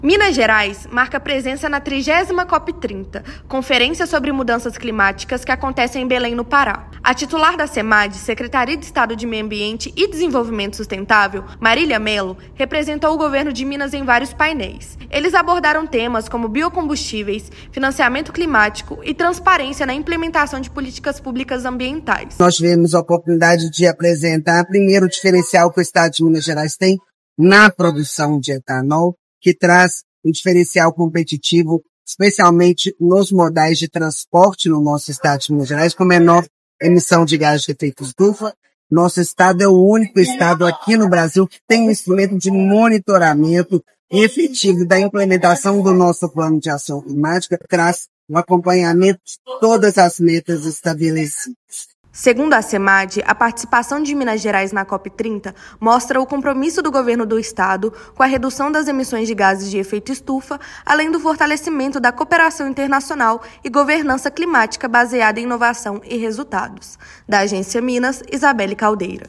[RÁDIO] Minas Gerais apresenta soluções para descarbonização e transparência climática na COP30
Estado reforça protagonismo em biocombustíveis, financiamento climático e monitoramento das ações de mitigação e adaptação. Ouça matéria de rádio.